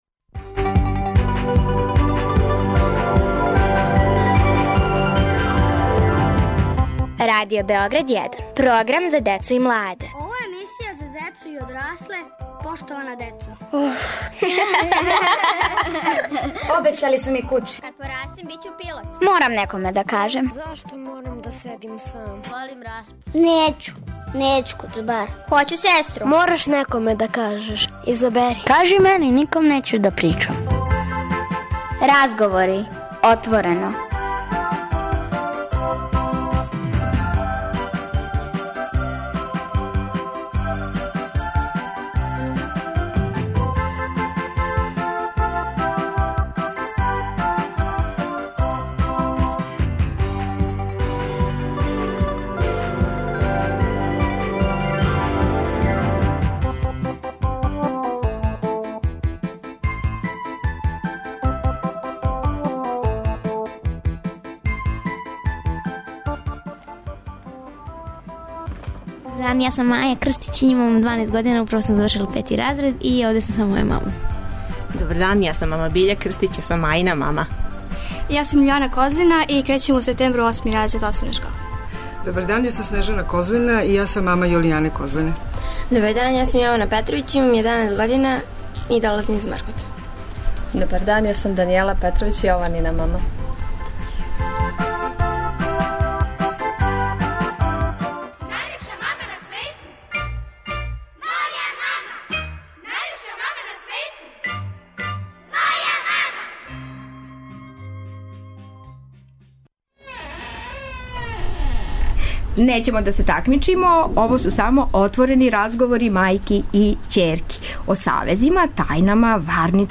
Пред нама су отворени разговори мајки и ћерки.